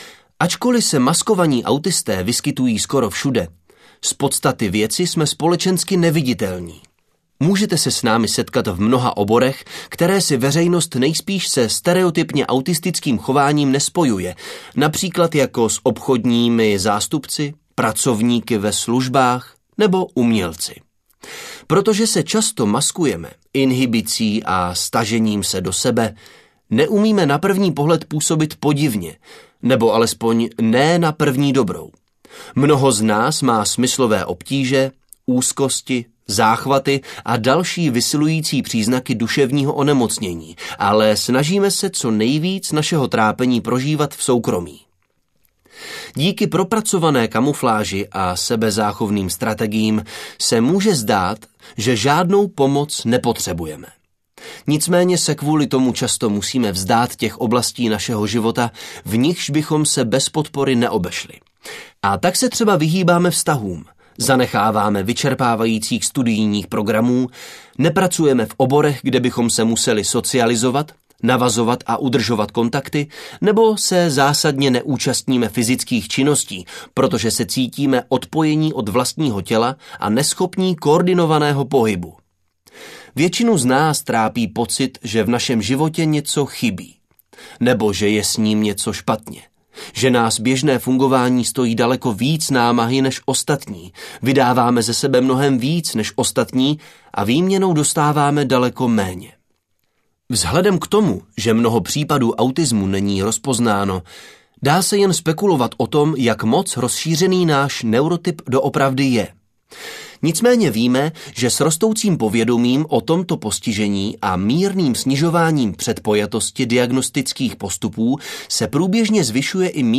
Audiokniha Autismus bez masky - Devon Price | ProgresGuru